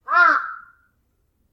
cuervo3
crow3.mp3